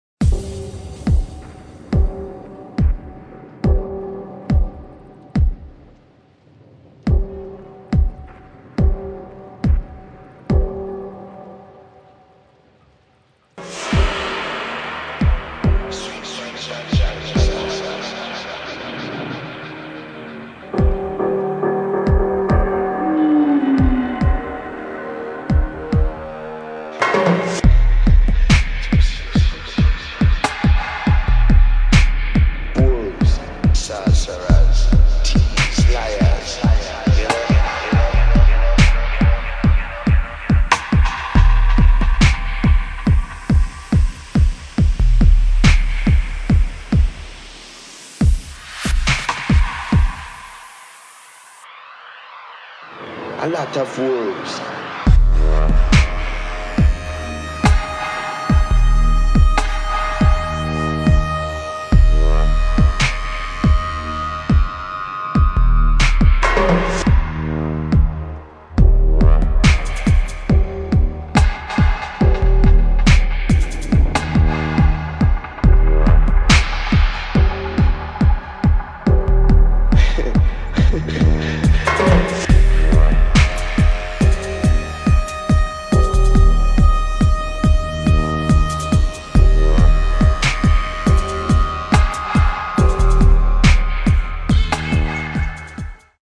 [ DUB | REGGAE | DUBSTEP ]